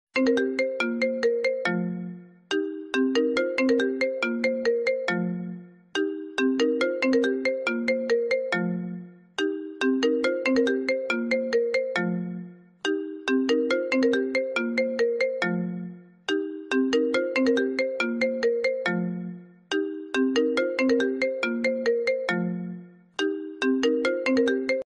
Звуки вибрации iPhone
iPhone 13 — уникальный рингтон